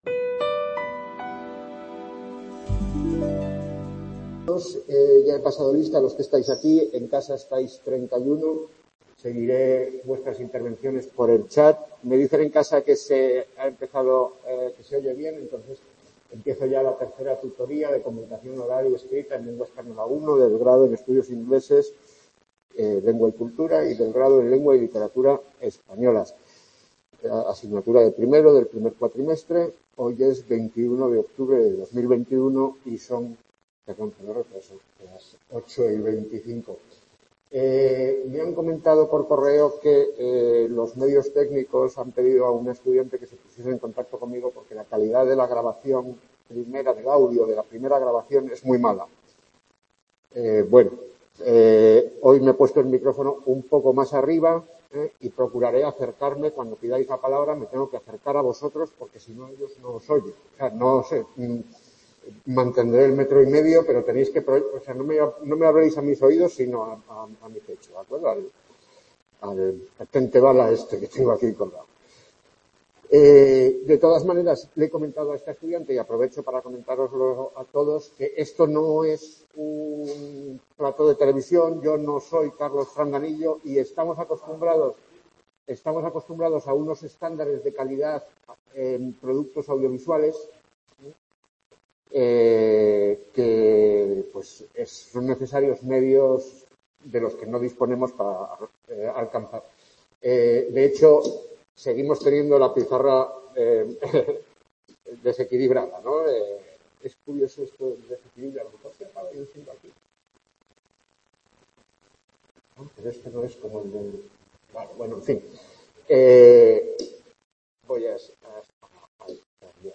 Tutoría Comunicación oral y escrita en lengua española